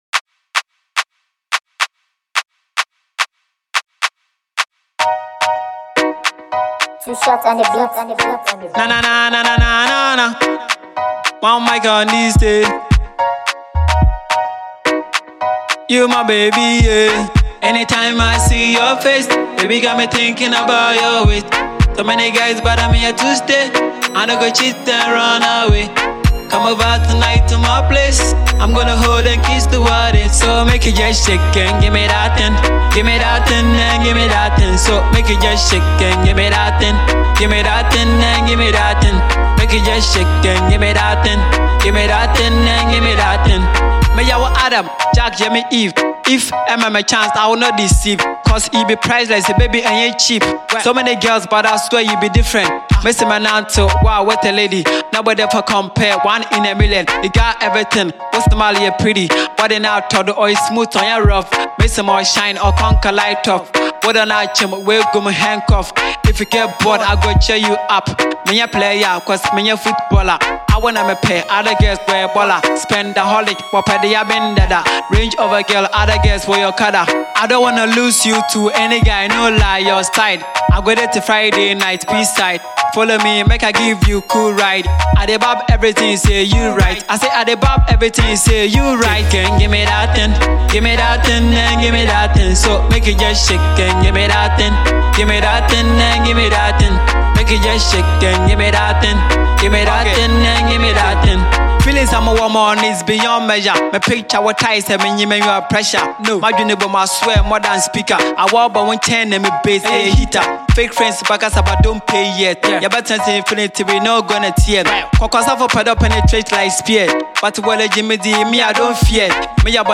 Ghanaian fanti rapper